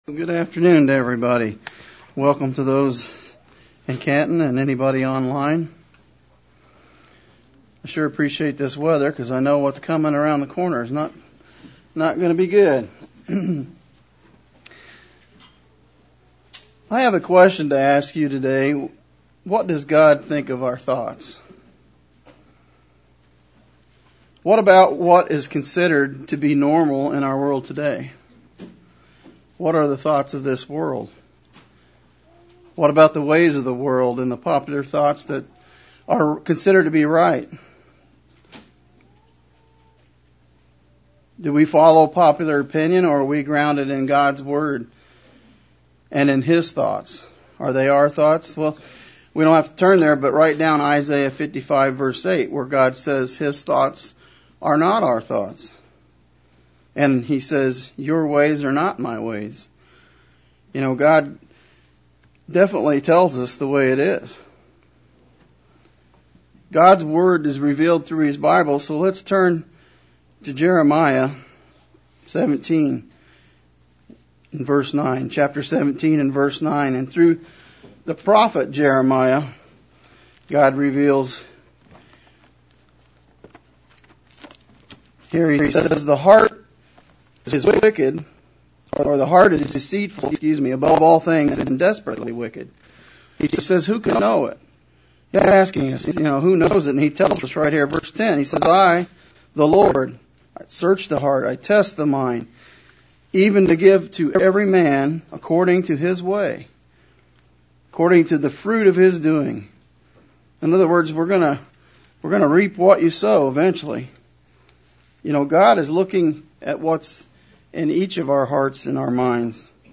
Split sermon looking at the work we need to do on what we do, think about, and put into our minds as we strive to measure up to what God expects of us.